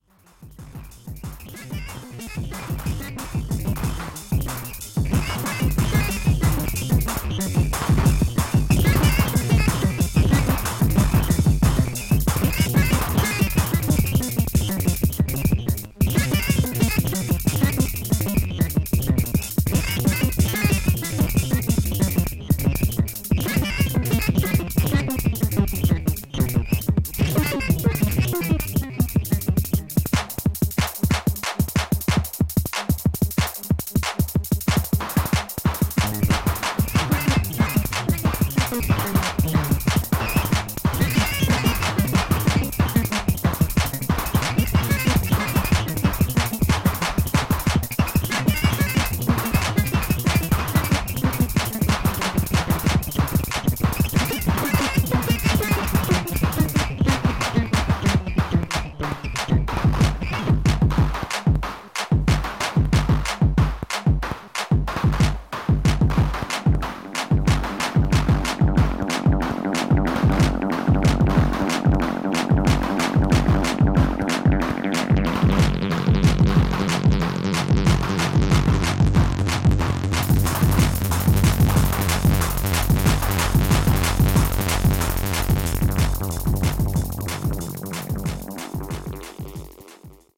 Dark raw pulsating acid techno
Electronix Techno Acid